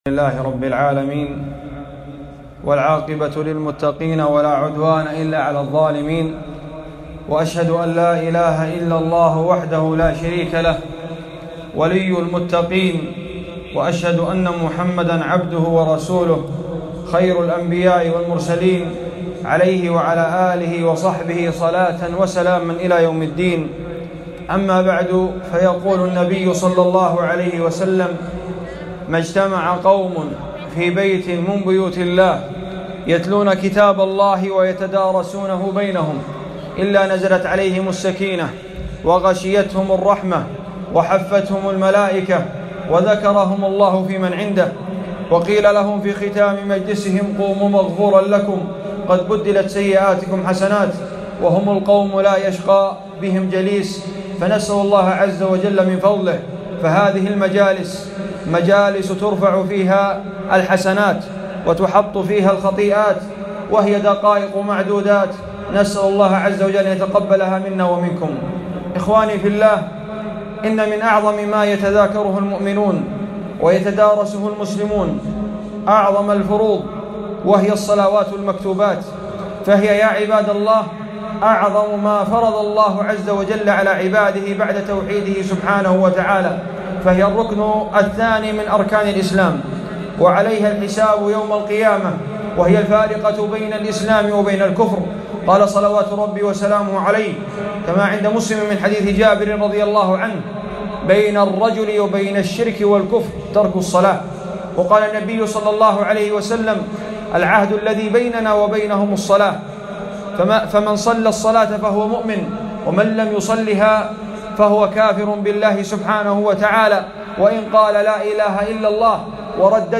محاضرة - فضائل صلاتي الفجر والعصر